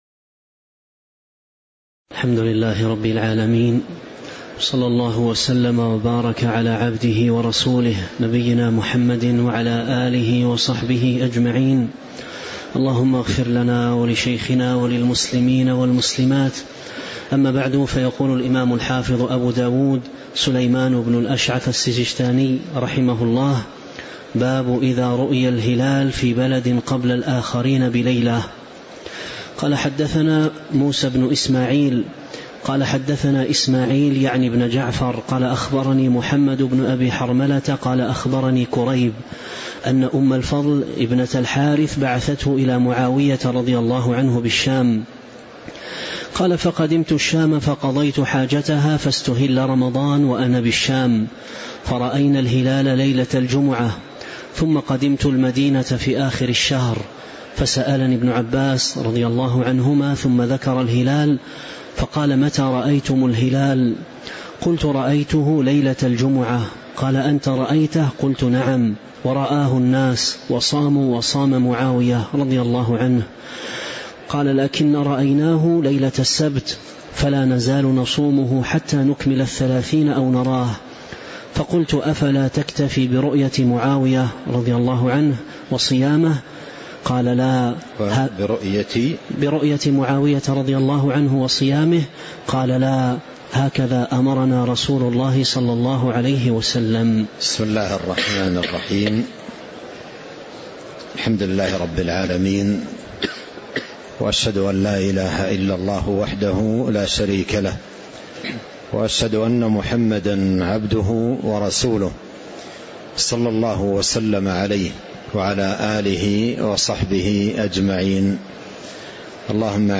تاريخ النشر ٤ رمضان ١٤٤٦ هـ المكان: المسجد النبوي الشيخ